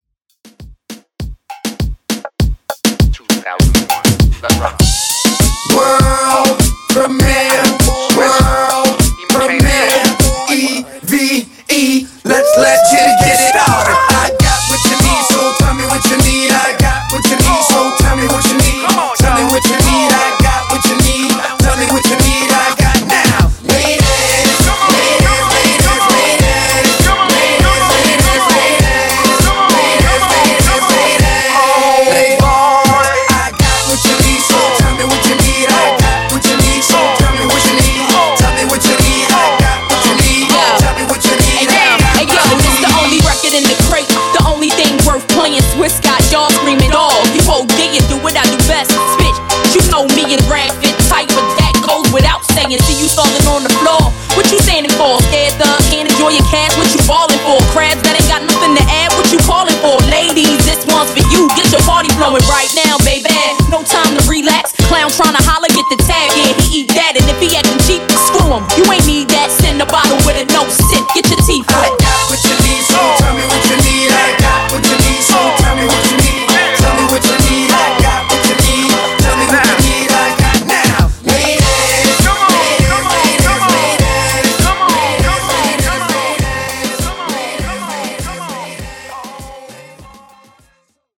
Genres: 2000's , HIPHOP , OLD SCHOOL HIPHOP
Clean BPM: 100 Time